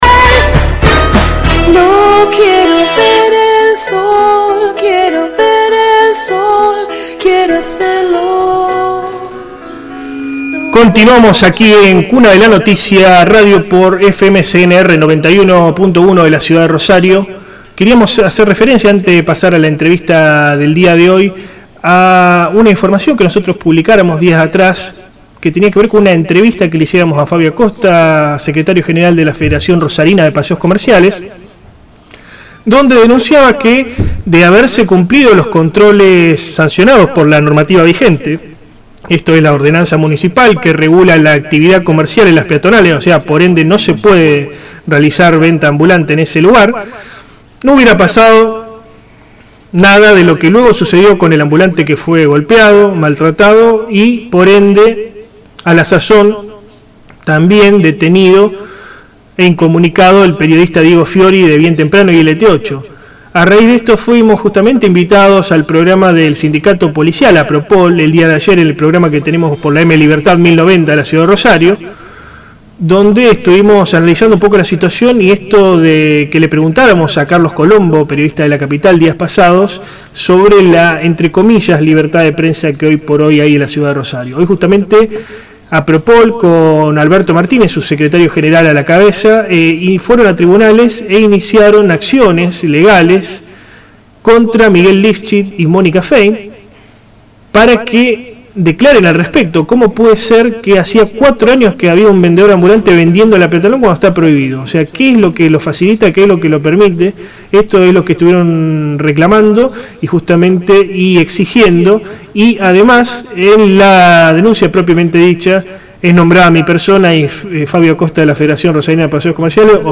MARIANO CABROL AUDIO ENTREVISTA
Cuna de la Noticia entrevistó al concejal de la ciudad de Villa Gobernador Gálvez, Mariano Cabrol.